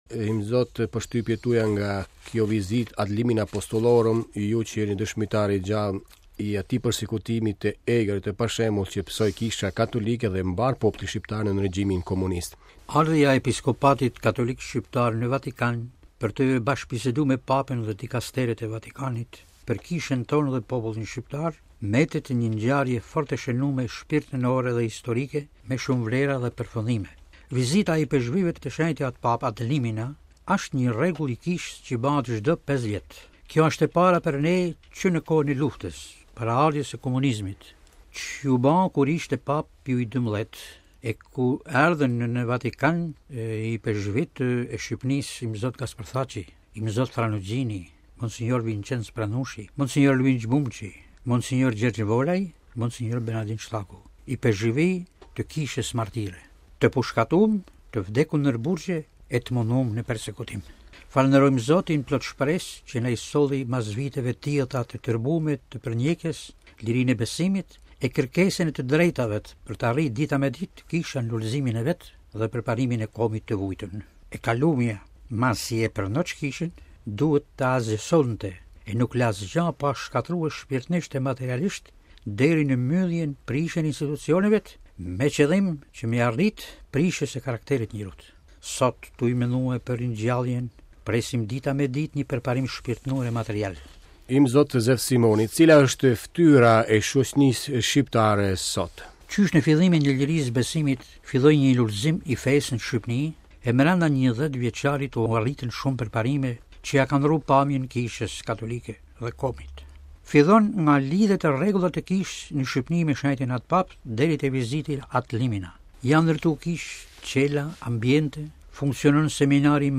Ja edhe porosia e tij nga intervista e realizuar me 2001 në Radio Vatikan me rastin e vizitës Ad Limina të ipeshkvijve të Shqipërisë në Vatikan.........RealAudio